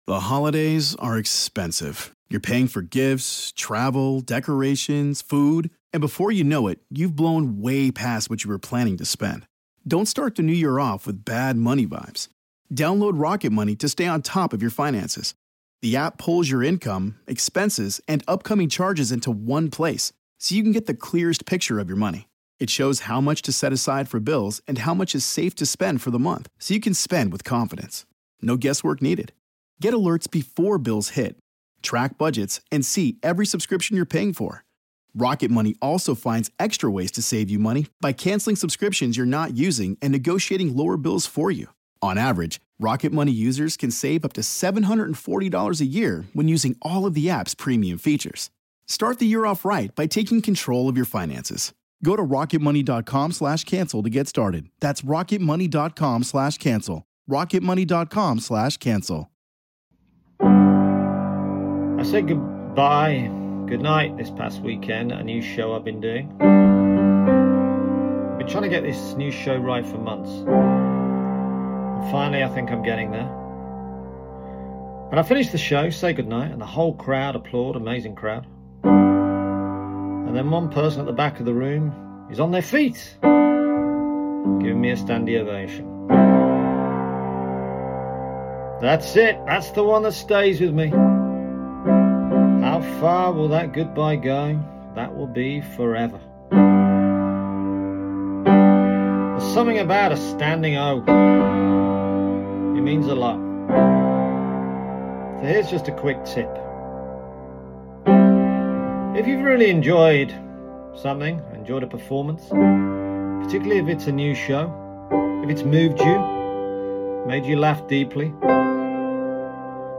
Standing O at my gig.